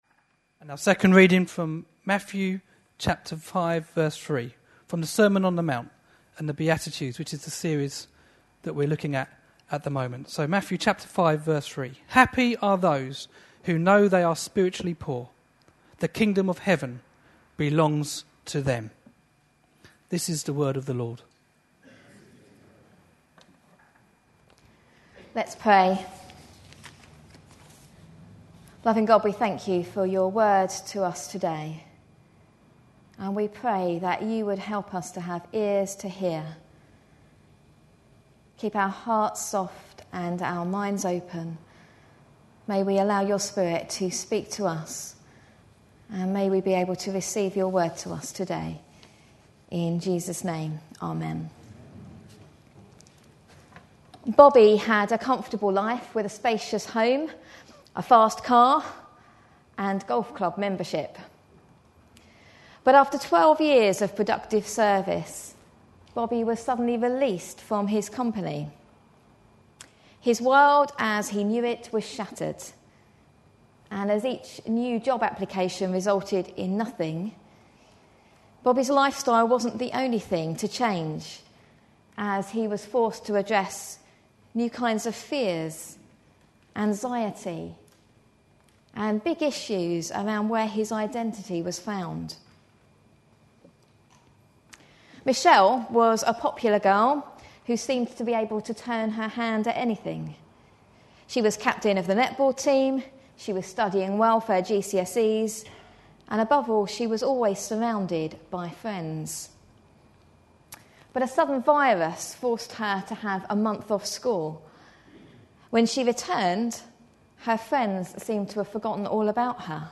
A sermon preached on 25th September, 2011, as part of our The Beatitudes. series.